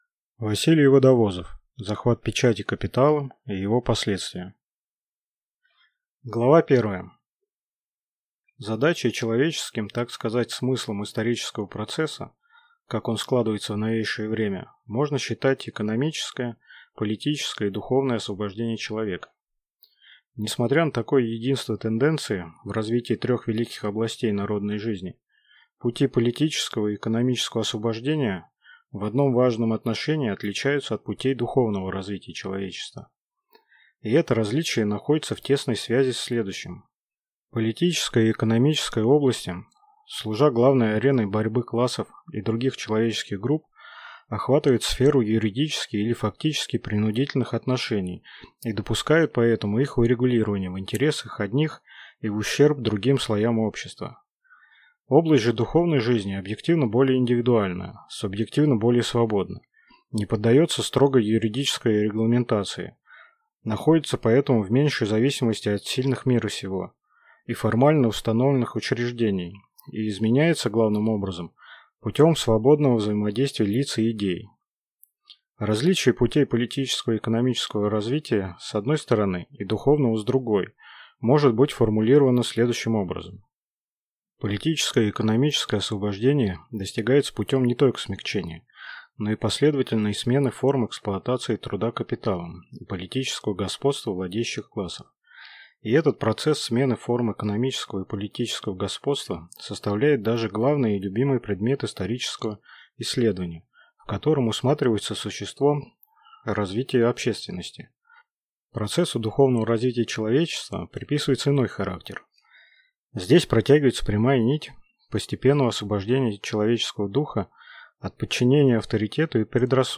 Аудиокнига Захват печати капиталом и его последствия | Библиотека аудиокниг